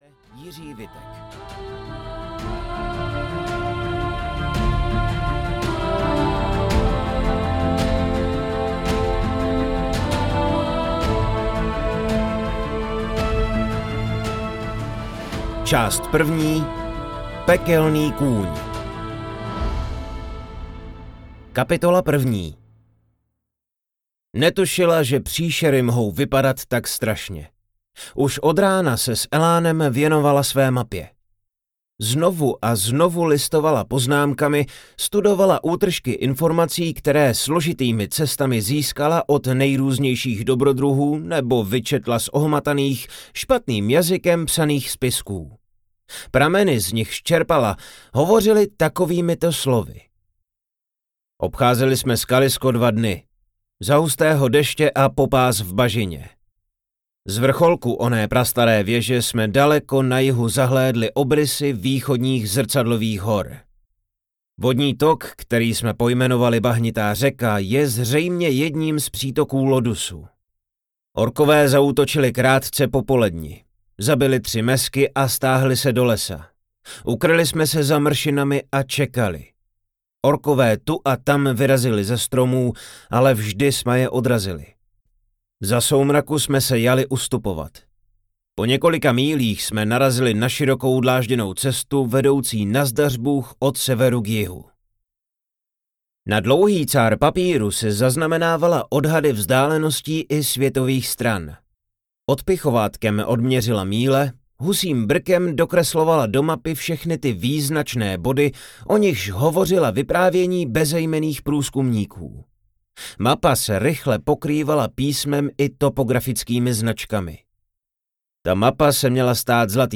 Orcigard audiokniha
Ukázka z knihy